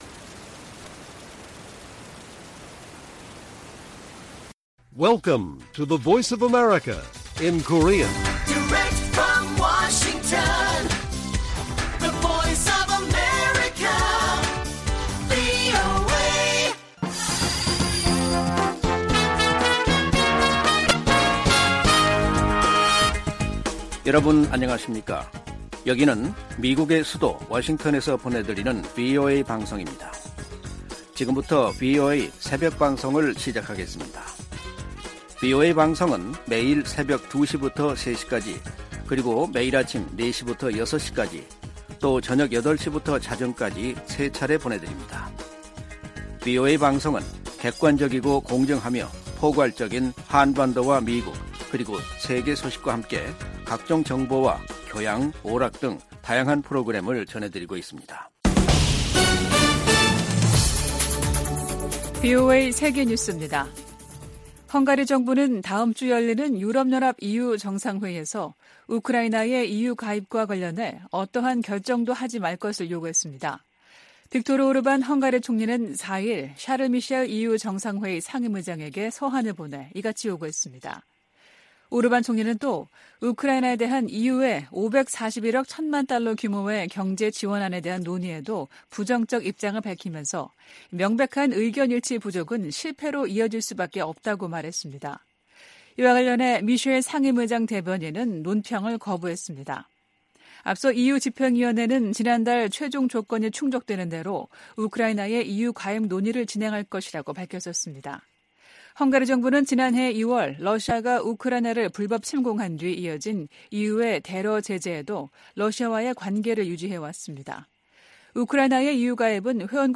VOA 한국어 '출발 뉴스 쇼', 2023년 12월 6일 방송입니다. 미국 정부가 남북한의 정찰 위성 발사에 대해 이중 기준을 가지고 있다는 북한의 주장을 일축했습니다. 국제 법학 전문가들도 북한이 정찰위성 발사에 대해 국제법적 정당성을 강변하는 것은 국제 규범 위반이라고 지적했습니다. 미 하원 군사위원회 부위원장이 북한 정찰위성 발사를 강력 규탄했습니다.